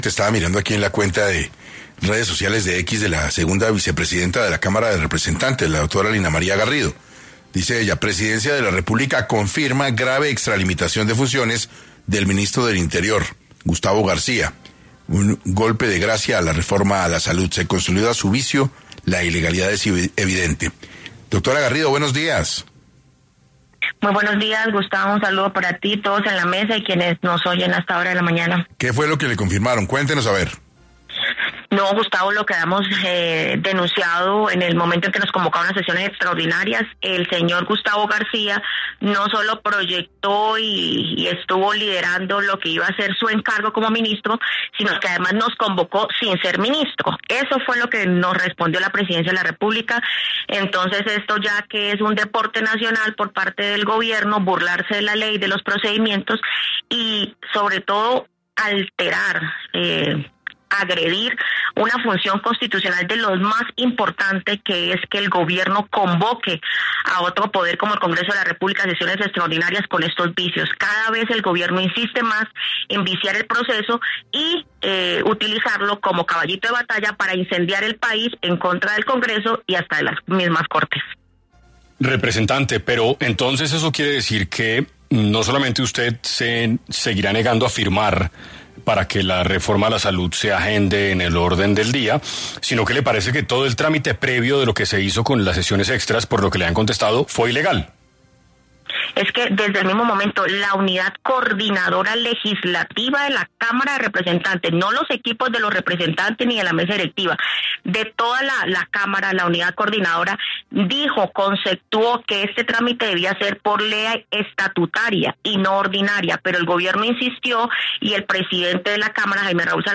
En entrevista con 6AM de Caracol radio, la representante a la Cámara, Lina Garrido, denunció sobrelimitación de funciones del Ministro del Interior, Gustavo García, ya que denuncia que en el momento que se convocó a sesiones extra, el ministro aún no asumía su cargo.